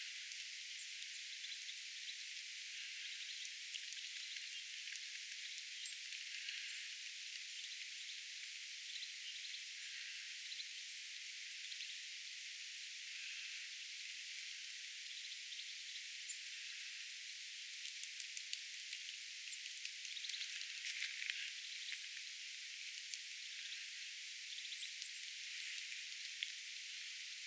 töyhtötiaisen ääntelyä